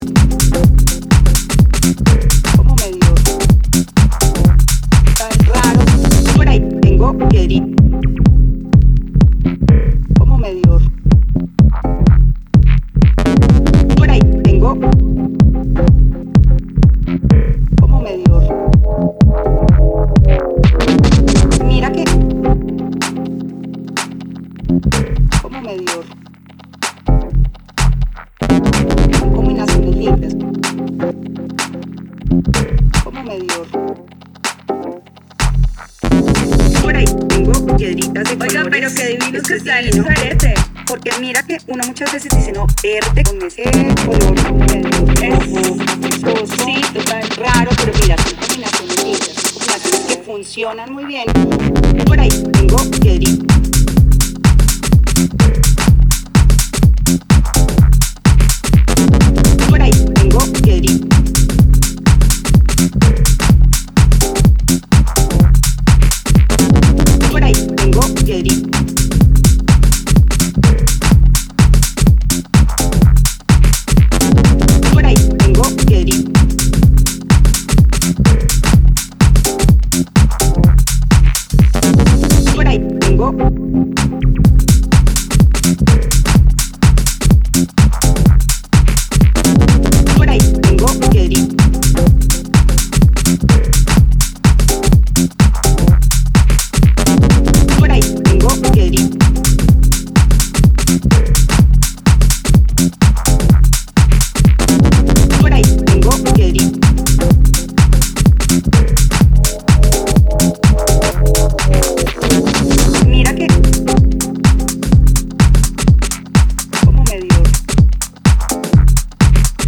Raw, cinematic, and authentic.